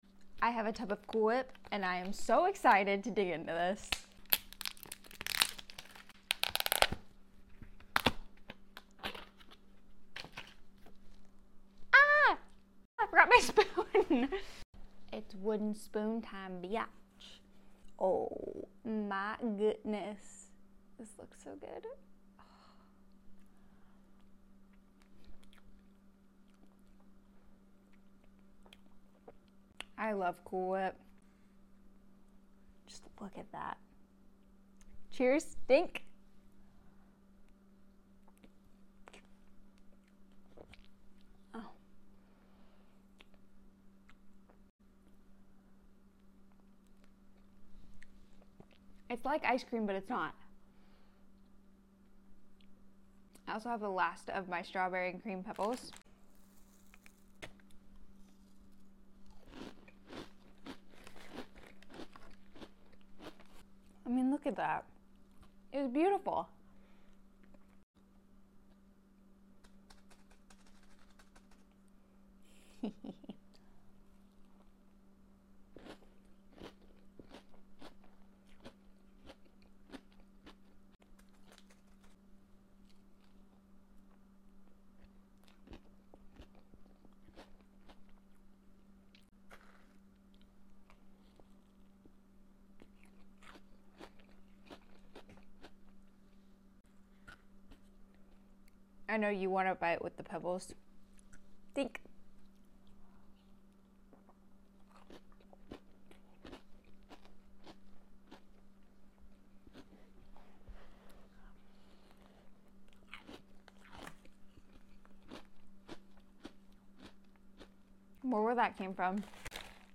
Cool whip mukbang